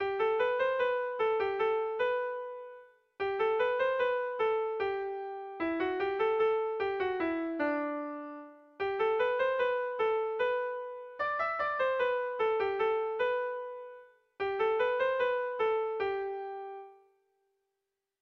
Air de bertsos - Voir fiche   Pour savoir plus sur cette section
Seiko berezia, 3 puntuz (hg) / Hiru puntuko berezia (ip)
ABD